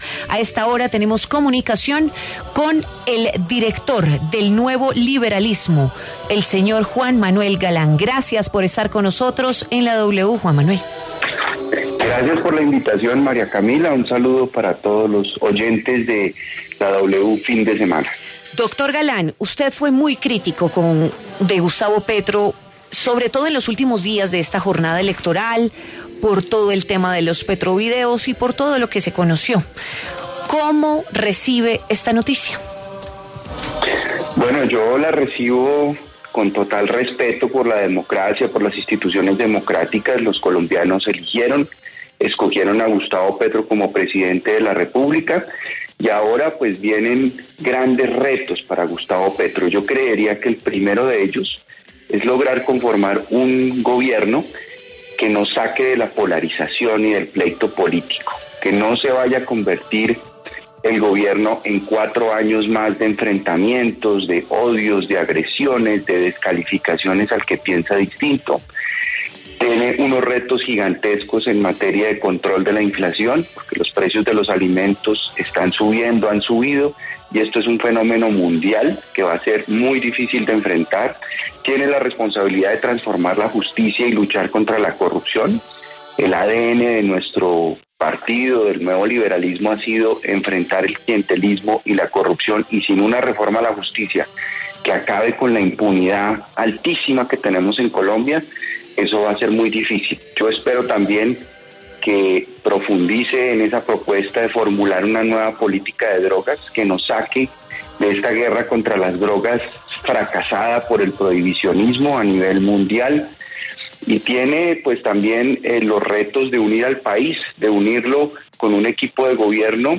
En diálogo con La W, Juan Manuel Galán se pronunció sobre la elección de Gustavo Petro en segunda vuelta como presidente de la República.
Juan Manuel Galán, director del Nuevo Liberalismo, conversó con La W tras las elecciones del pasado 19 de junio en las que Gustavo Petro fue elegido presidente de la República.